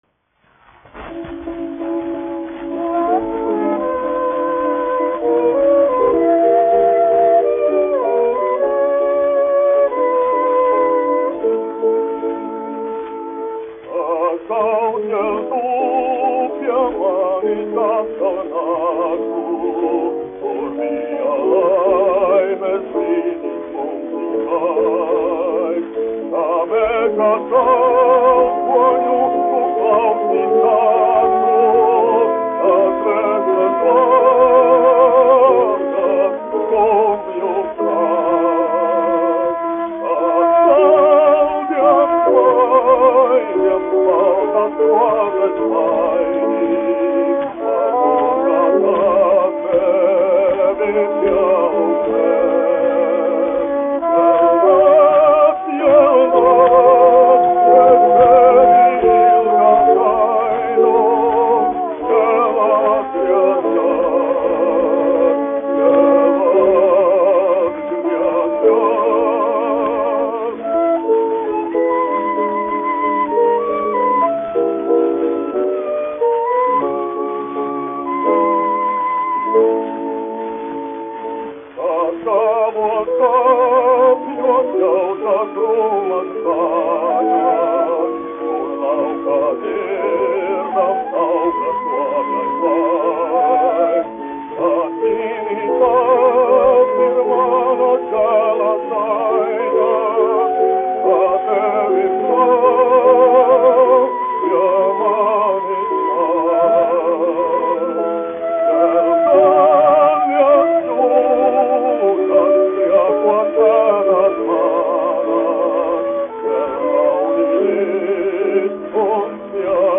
1 skpl. : analogs, 78 apgr/min, mono ; 25 cm
Dziesmas (zema balss) ar instrumentālu ansambli
Latvijas vēsturiskie šellaka skaņuplašu ieraksti (Kolekcija)